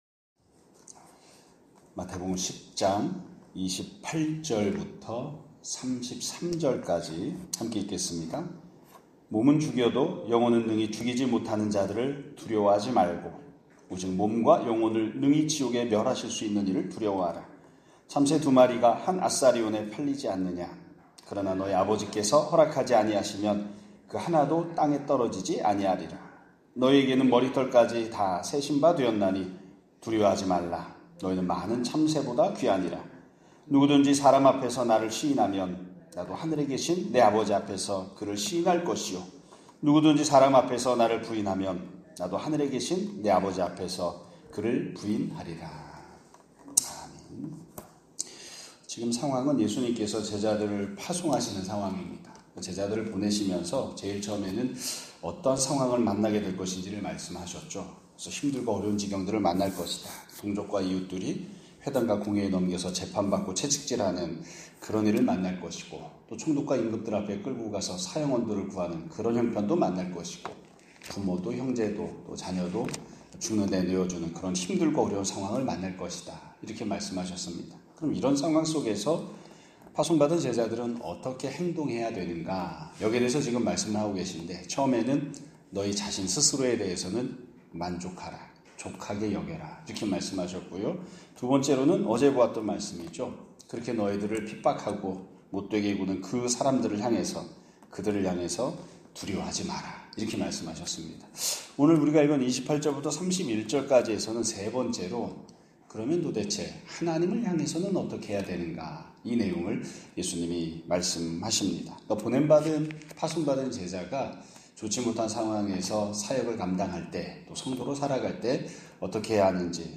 2025년 8월 13일 (수요일) <아침예배> 설교입니다.